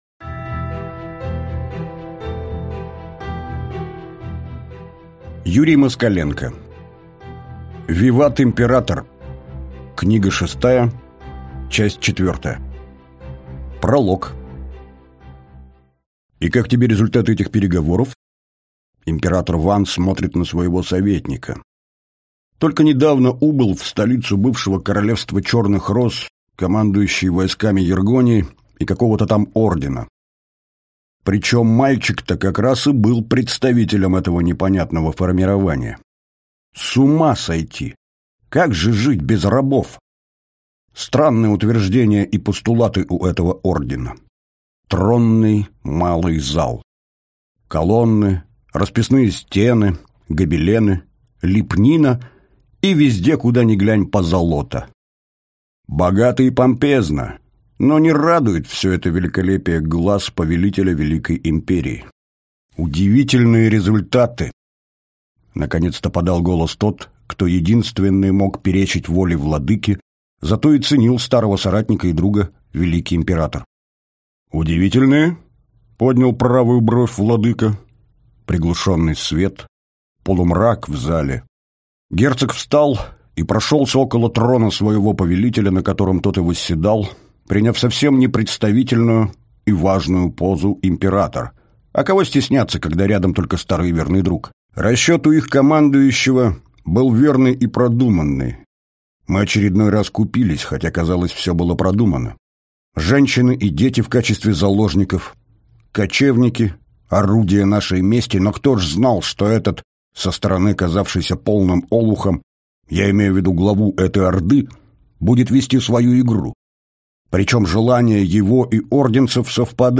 Аудиокнига Виват, император. Книга шестая. Часть четвёртая | Библиотека аудиокниг